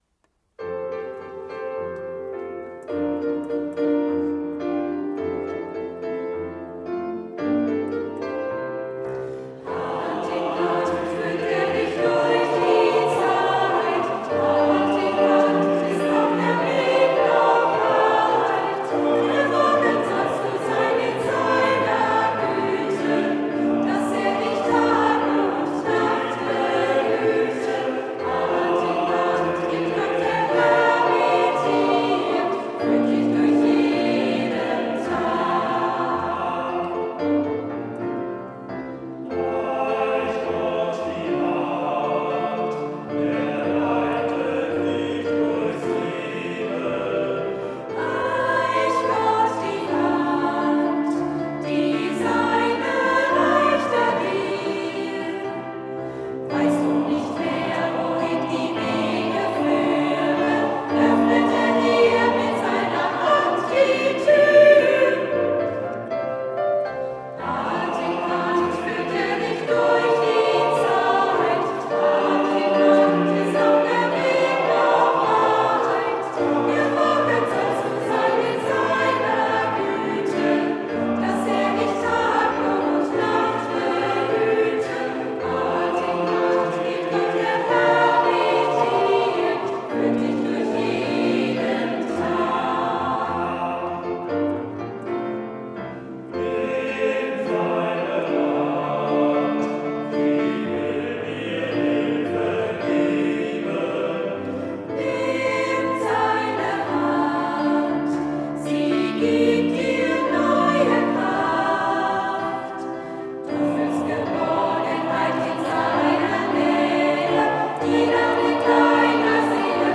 Neuer christlicher Chorsatz